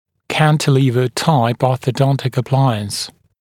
[‘kæntɪliːvə-taɪp ˌɔːθə’dɔntɪk ə’plaɪəns][‘кэнтили:вэ-тайп ˌо:сэ’донтик э’плайэнс]ортодонтический аппарат консольного типа